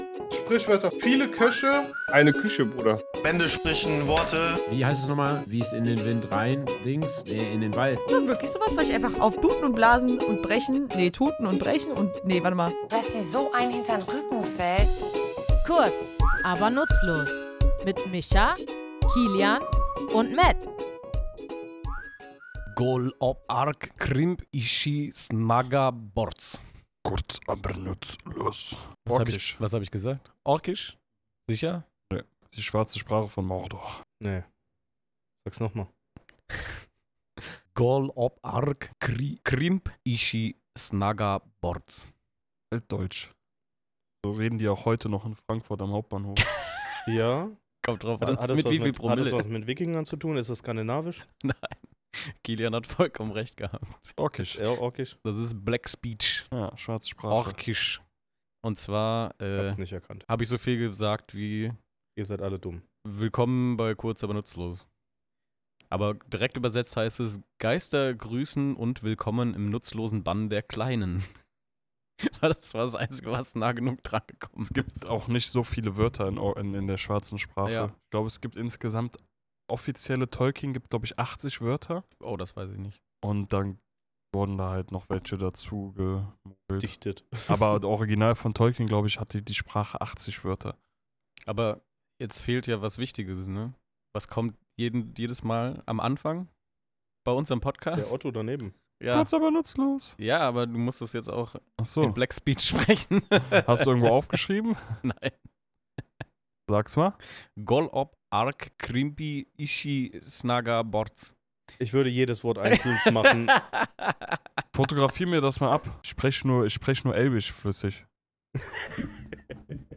Wir, drei tätowierende Sprachnerds, tauchen in unserem Tattoostudio in die Welt der Mythen, Literatur und Popkultur ein und verfolgen die sprachliche Spur des Orks von der Antike bis ins Online-Rollenspiel.